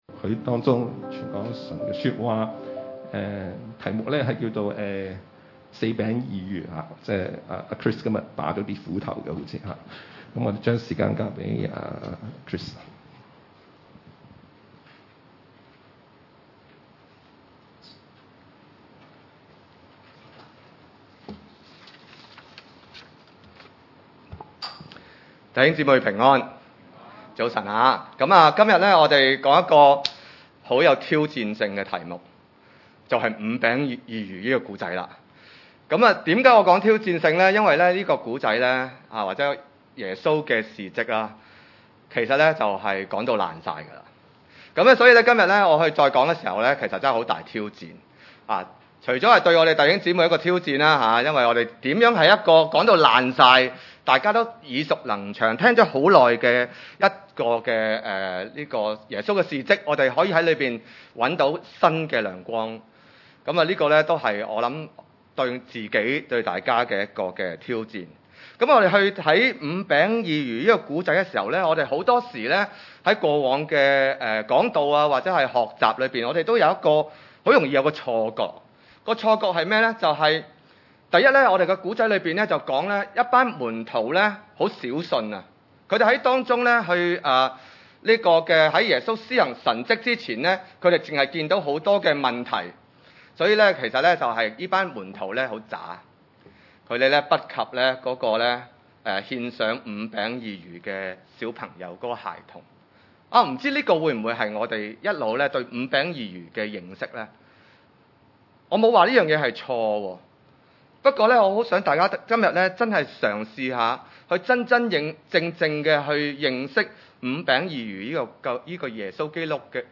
約六1-15節 崇拜類別: 主日午堂崇拜 這事以後，耶穌渡過加利利海，就是提比哩亞海。